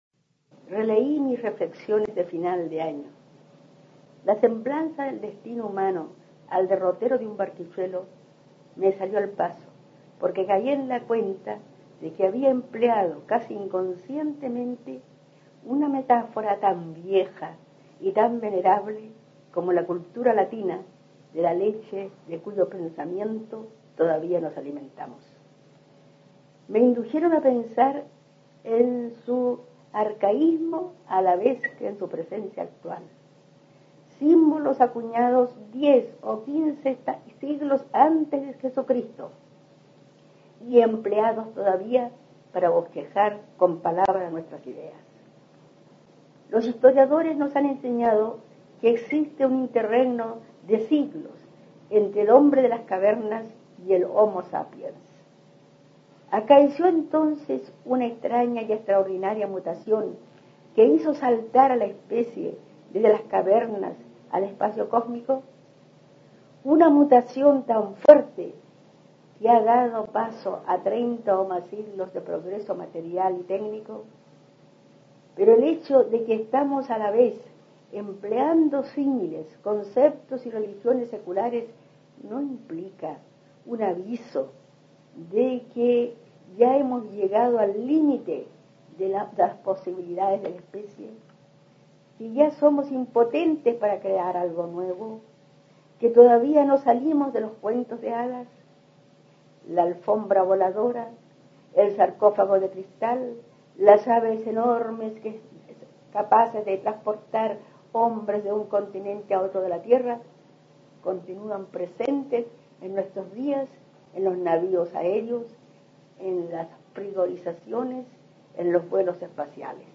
Aquí se puede escuchar a la destacada profesora chilena Amanda Labarca (1886-1975) leyendo su ensayo "¿A dónde vas perdida?", trabajo cuyo título remite a Lope de Vega, y en el que plantea sus cuestionamientos al supuesto progreso del ser humano.
Ensayo